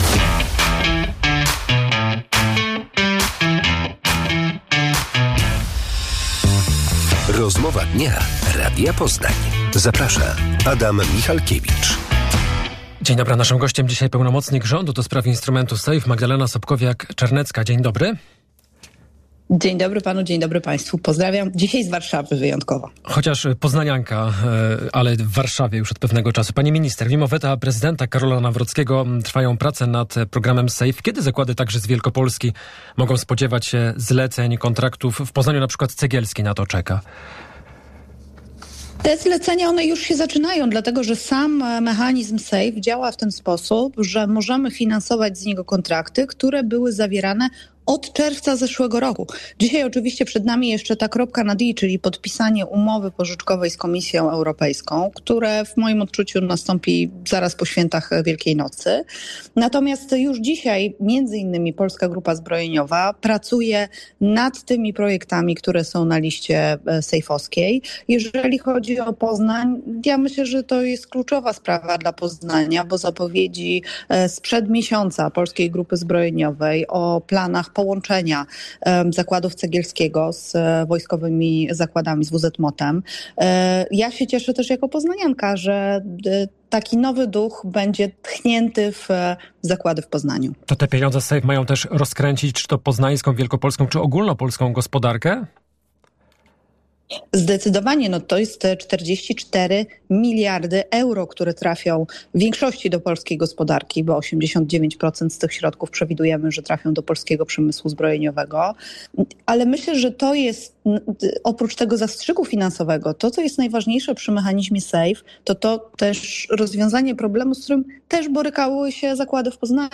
Po Wielkanocy Polska ma podpisać umowę z Komisją Europejską w sprawie Safe. W Rozmowie Dnia Radia Poznań pełnomocnik rządu do spraw Safe Magdalena Sobkowiak-Czarnecka mówiła, że zlecenia dla zakładów zbrojeniowych już się zaczynają.
Autor: , Tytuł: Rozmowa Dnia - Magdalena Sobkowiak-Czarnecka